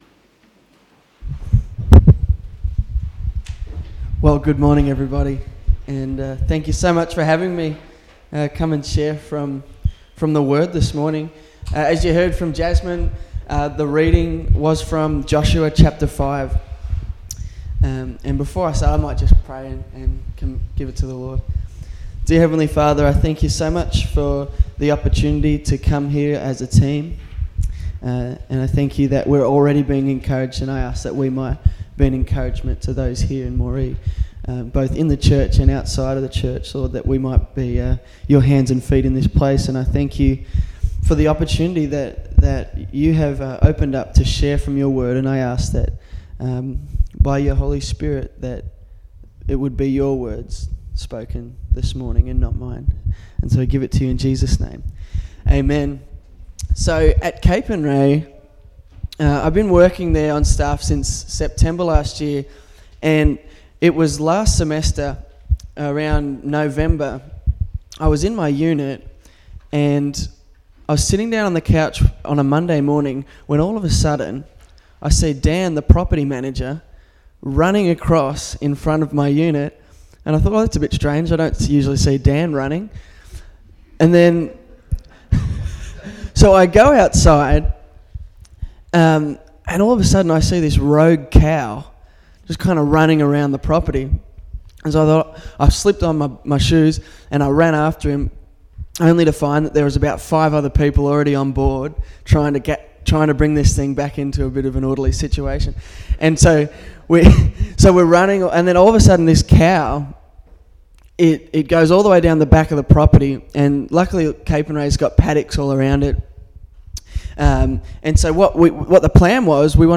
30.4.17-Sunday-Service-Are-you-for-me-or-against-me.mp3